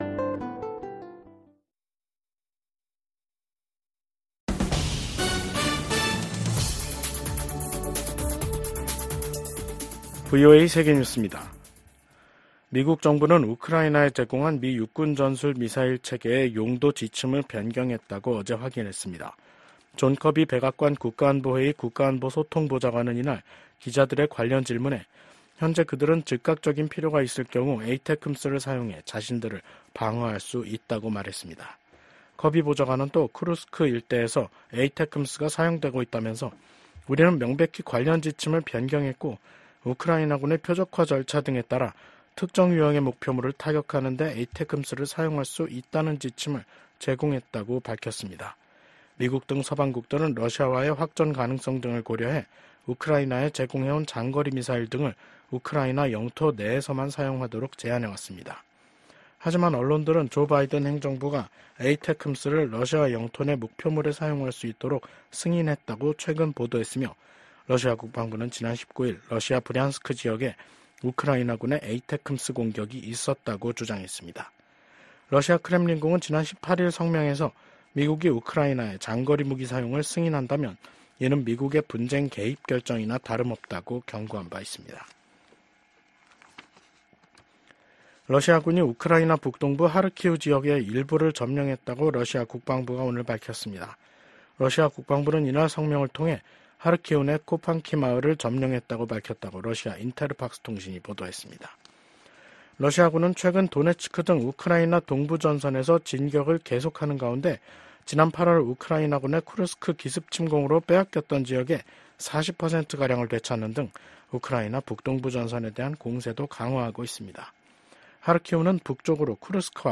VOA 한국어 간판 뉴스 프로그램 '뉴스 투데이', 2024년 11월 26일 2부 방송입니다. 미국은 북한군이 현재 우크라이나로 진격하지는 않았다고 밝혔습니다. 러시아가 북한에 파병 대가로 이중용도 기술과 장비를 판매하고 있다고 미 국무부가 밝혔습니다. 북러 군사 밀착이 북한 군의 러시아 파병으로까지 이어지고 있는 가운데 북중 관계는 여전히 냉랭한 기운이 유지되고 있습니다.